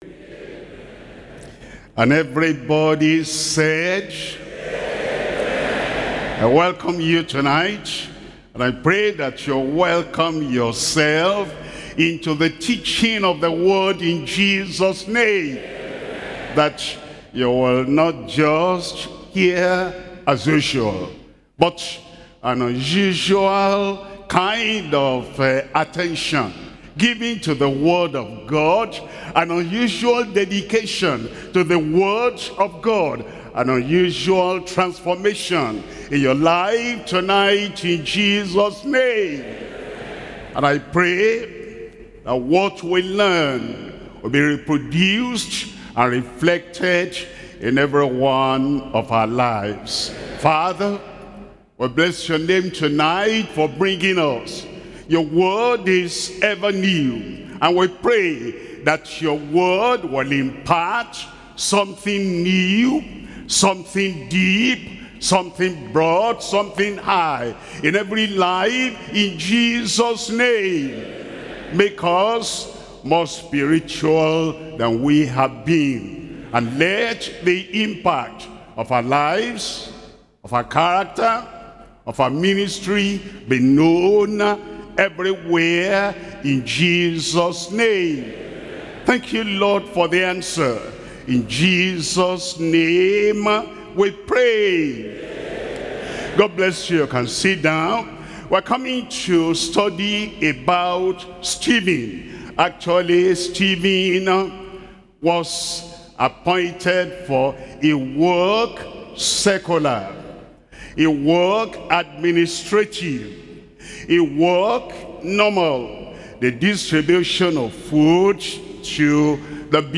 Sermons – Deeper Christian Life Ministry, Plaistow Region, UK